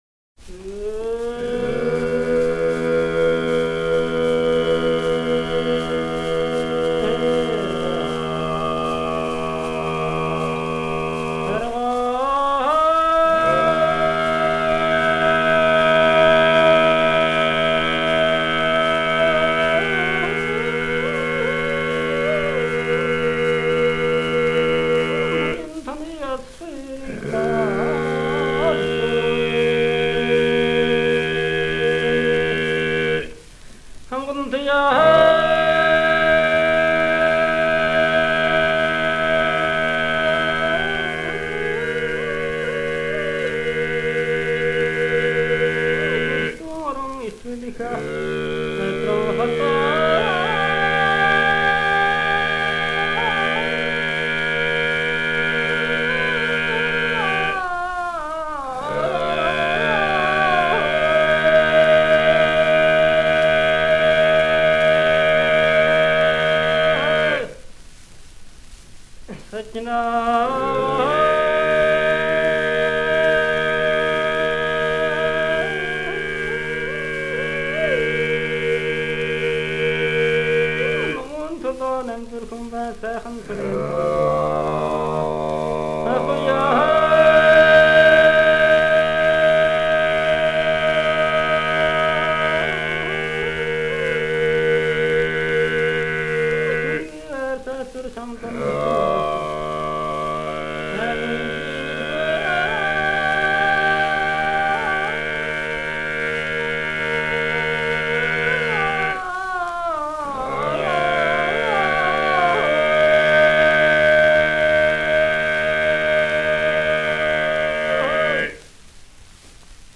Genre: Early Ethnic